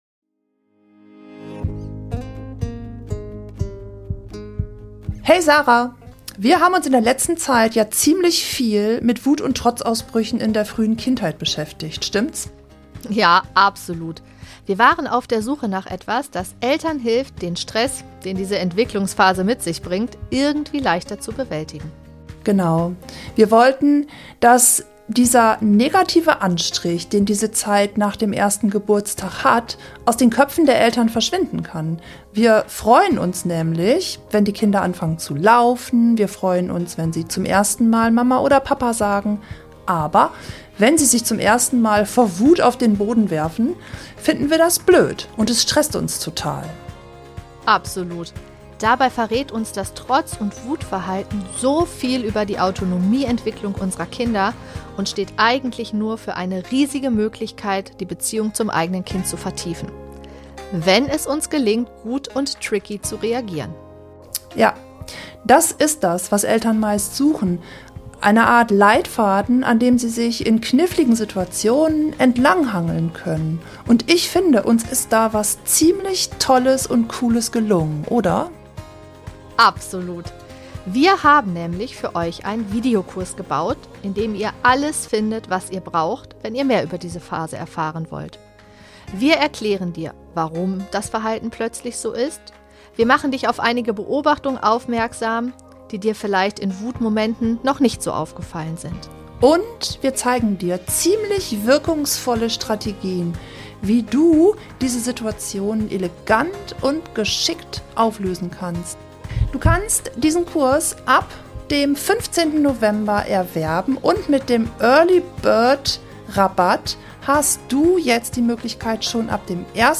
In dieser Folge veröffentlichen wir ein ganz persönliches Interview mit einer Mutter, die in einem patchwork- System lebt und über Schönes/ Herausforderndes und den Alltag in Patchwork Familien ...